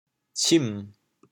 「沁」字用潮州話怎麼說？
潮州发音 潮州 cim3